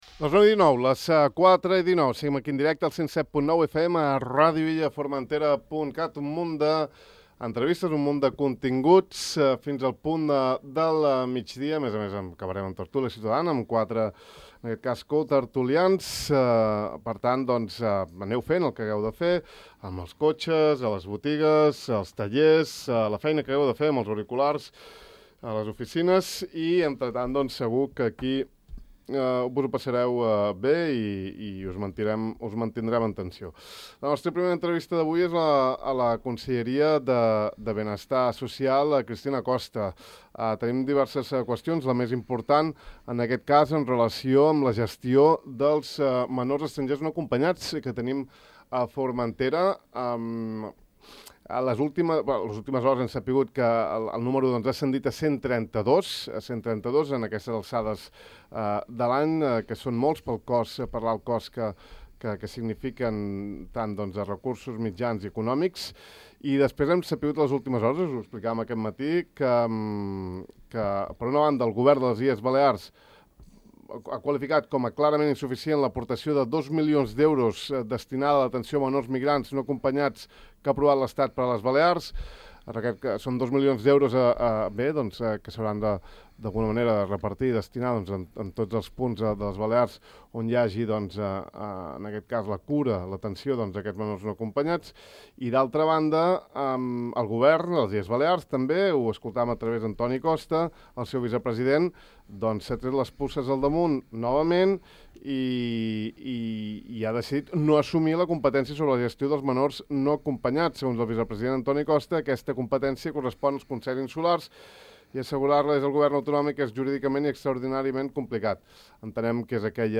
El cost de la manutenció, cura i educació d’aquests menors ascendeix a una quantia que ronda entre els 190 i 240 euros diaris, segons recorda la consellera de Benestar Social, Cristina Costa, en entrevista aquest matí al De far a far.